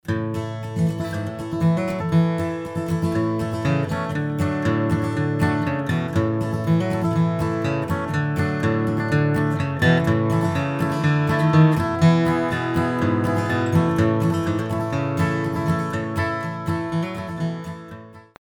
4/4-Begleitung, Basslinie "unter den Akkord",
flatpicking_bluegrass_bassline.mp3